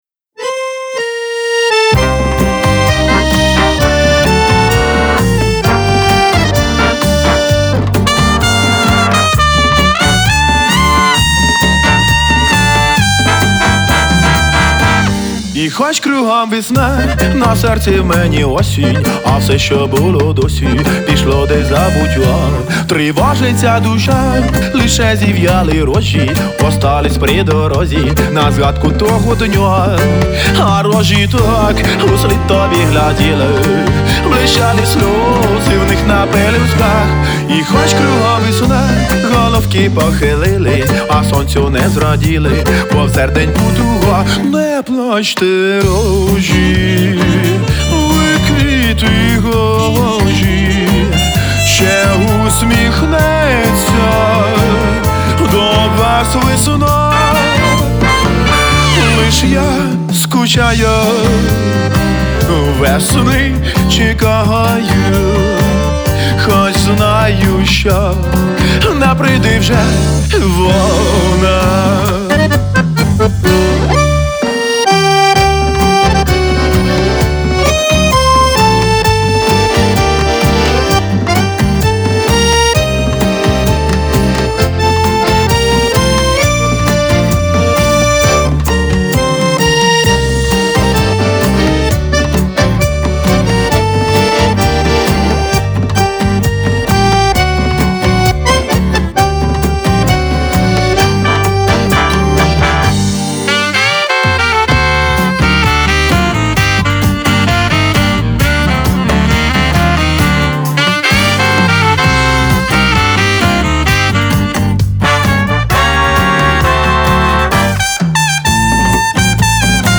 Стиль : retro pop